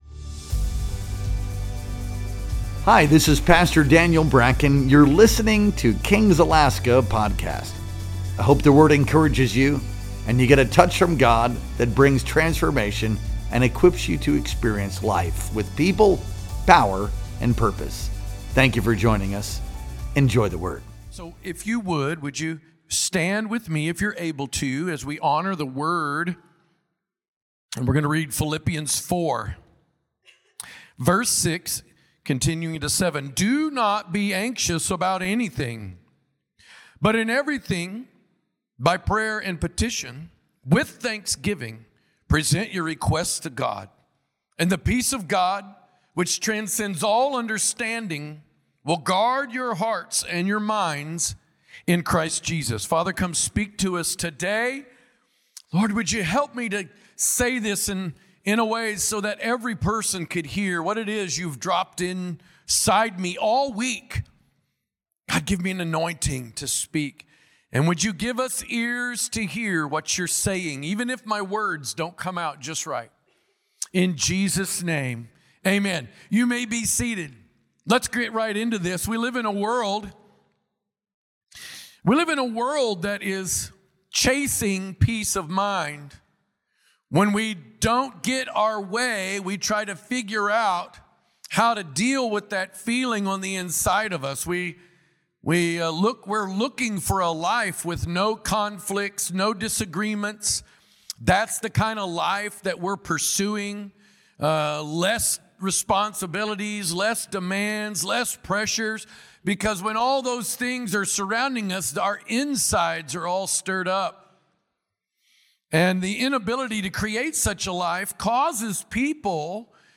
Our Sunday Morning Worship Experience streamed live on November 23rd, 2025.